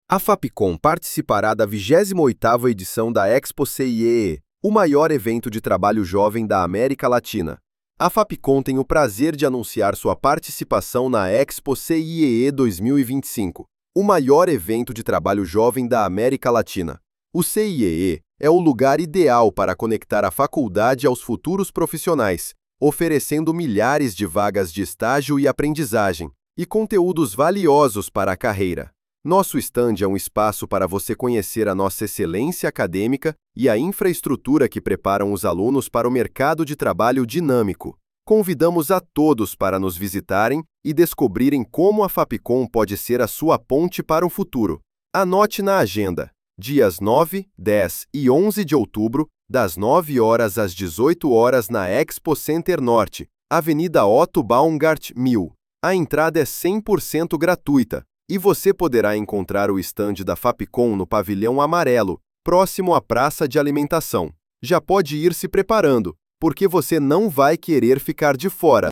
freepik__voiceover-generator__64753.mp3